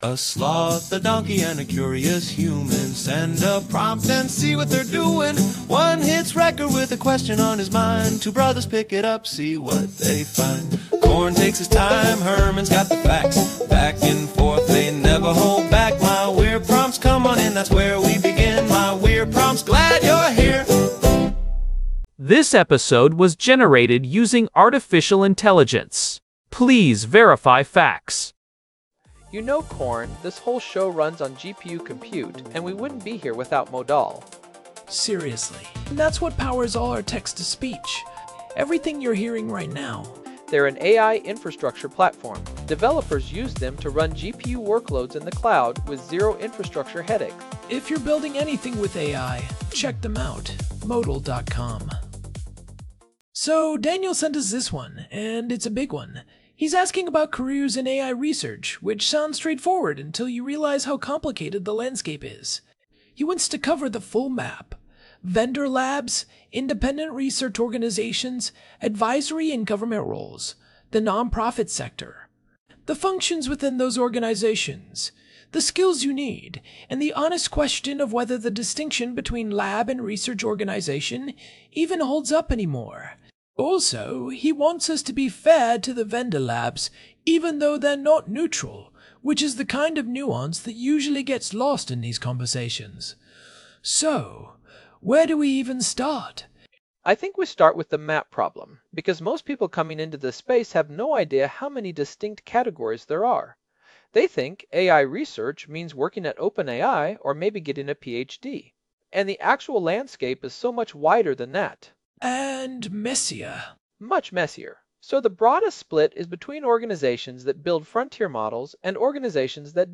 AI-Generated Content: This podcast is created using AI personas.